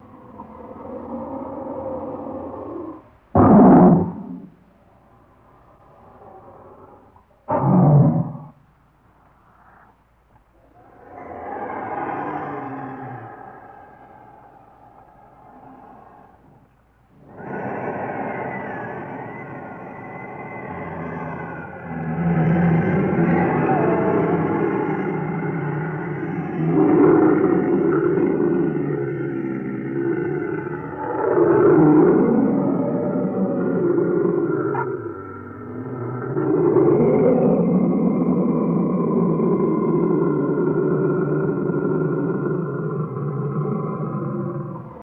Monster Breathing and Wheezing Mono